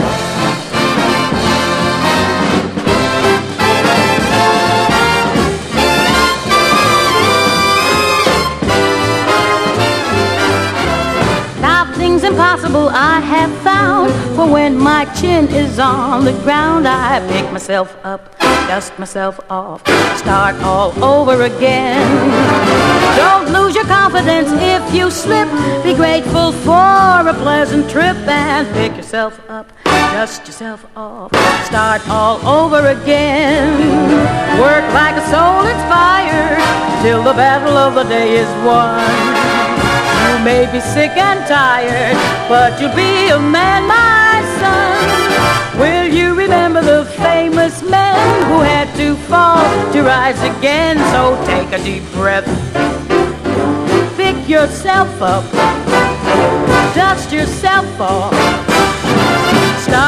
JAZZ / JAZZ VOCAL / CHORUS / JIVE / JAZZ CHORUS
ジャイヴィンでスウィンギーな人気作！
ヴァイブやピアノ、クラリネットが効いたスウィング・ジャズ作品！